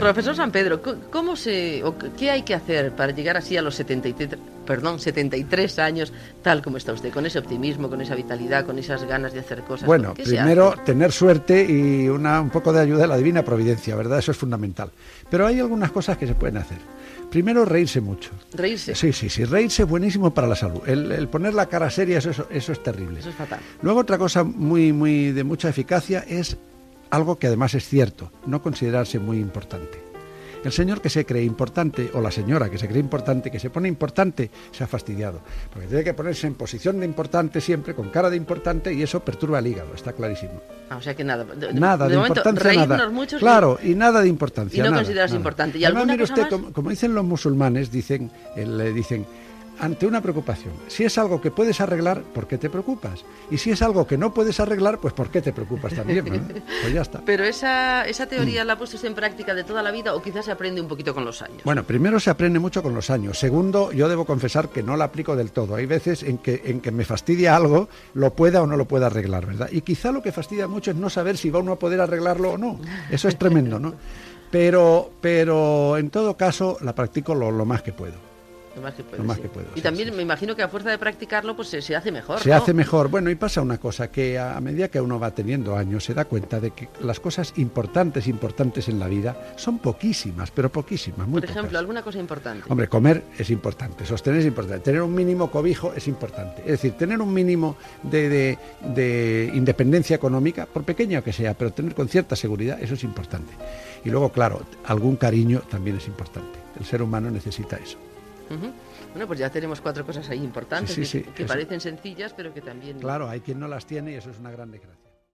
Entrevista a l'escriptor José Luis Sampedro sobre la vellesa i les coses importants de la vida